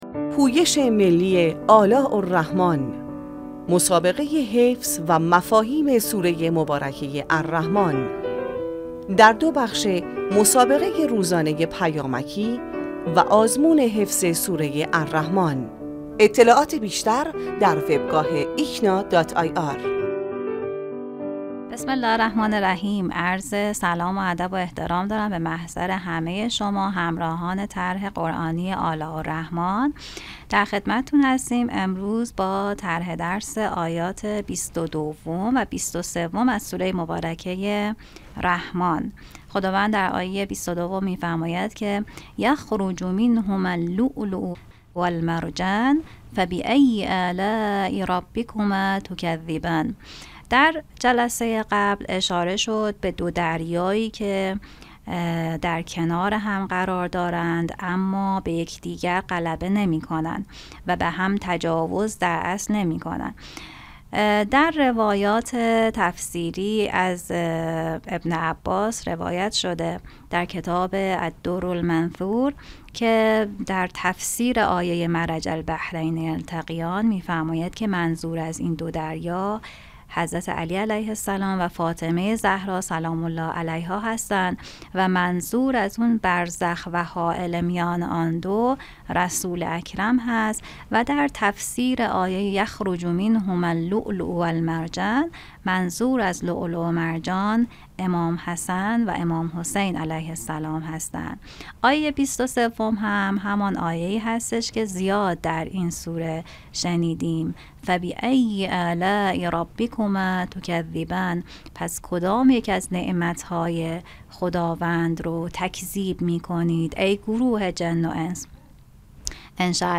عموم علاقه‌مندان در تمامی گروه‌های سنی می‌توانند با مشاهده فیلم آموزشی (حدود ۷ دقیقه) با تدریس دو نفر از اساتید قرآنی، که هر روز در ایکنا منتشر می‌شود، در کنار فراگیری مفاهیم، نکات تدبری و حفظ سوره الرحمن، در مسابقه روزانه پیامکی نیز شرکت و جوایز نقدی را شامل سه کارت هدیه پنج میلیون ریالی به قید قرعه (سه نفر از شرکت‌کنندگانی که پاسخ صحیح دو پرسش سه‌گزینه‌ای را داده‌اند) دریافت کنند.